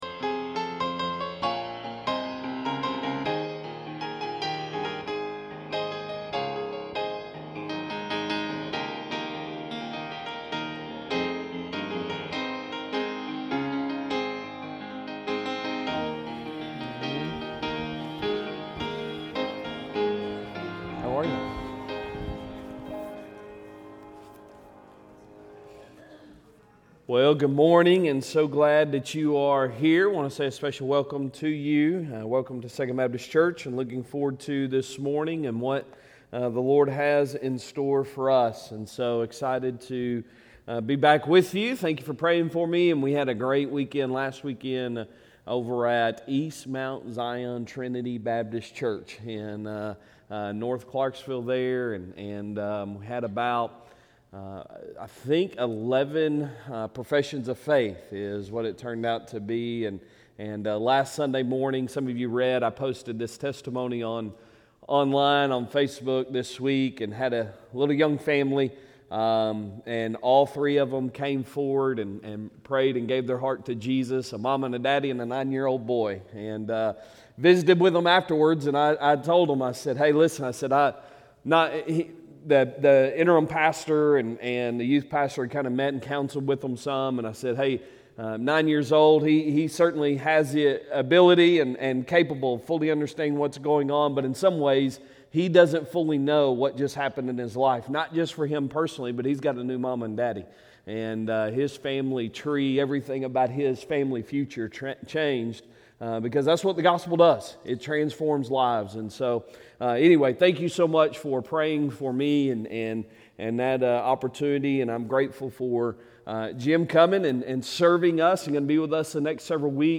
Sunday Morning Sermon February 09,2025